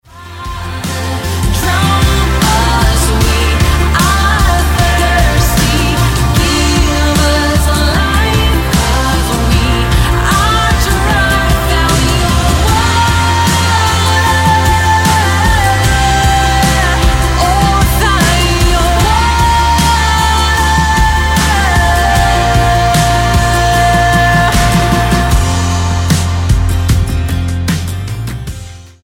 Style: Pop Approach: Praise & Worship